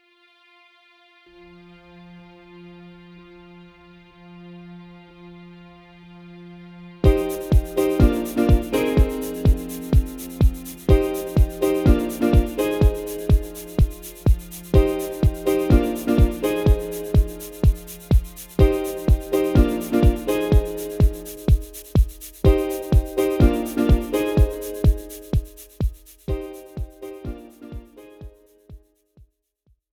This is an instrumental backing track cover.
• Key – Cm
• Without Backing Vocals
• No Fade